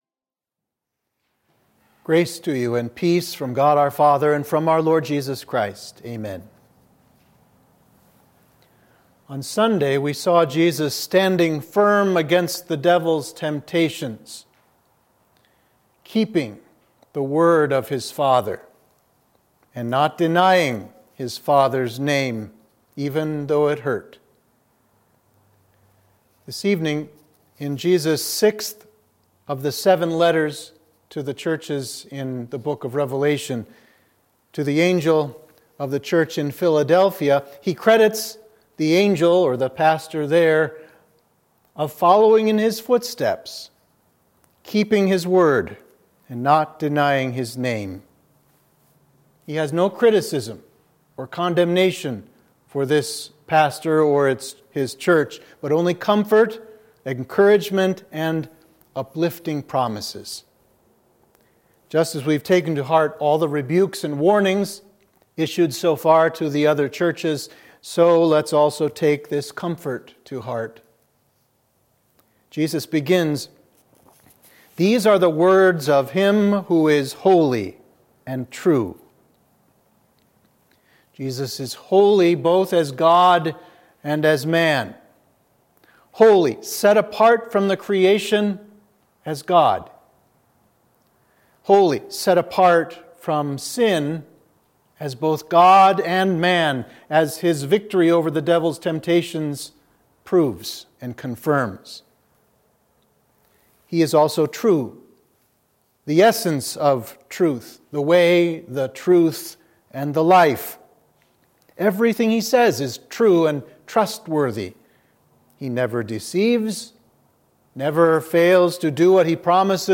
Sermon for Midweek of Invocavit